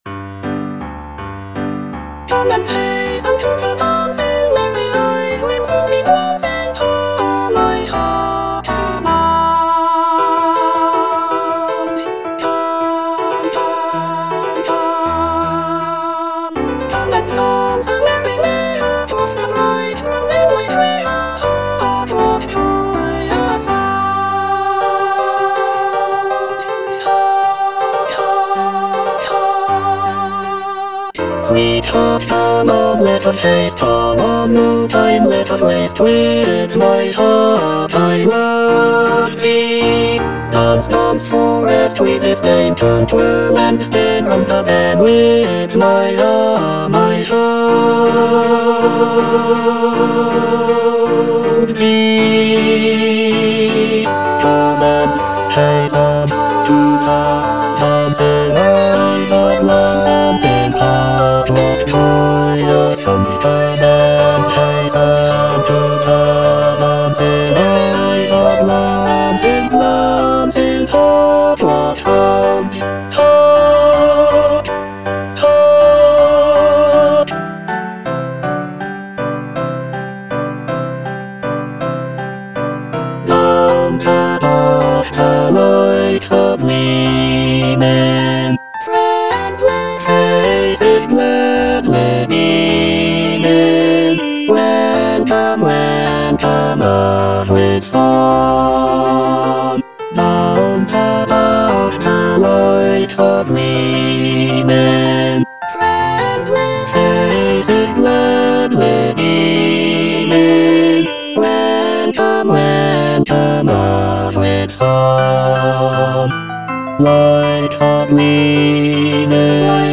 bass I) (Emphasised voice and other voices) Ads stop